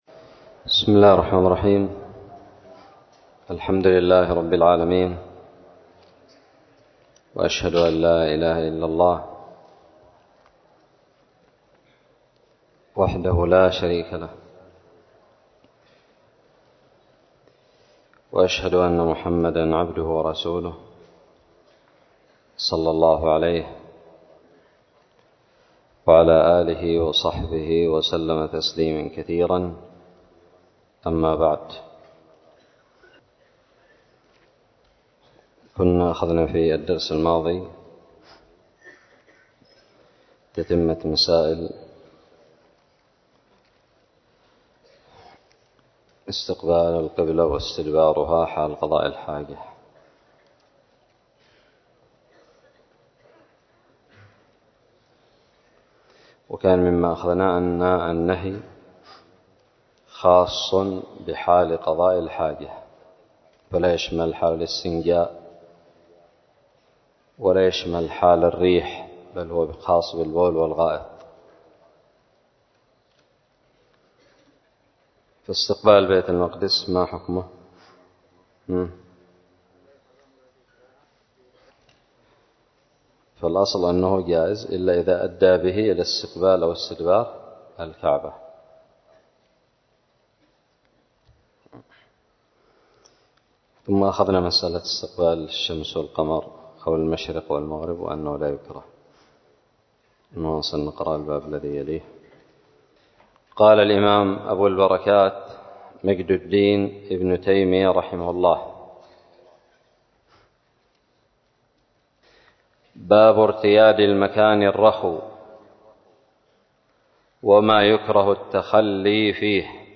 الدرس الرابع والخمسون من كتاب الطهارة من كتاب المنتقى للمجد ابن تيمية
ألقيت بدار الحديث السلفية للعلوم الشرعية بالضالع